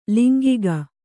♪ lingiga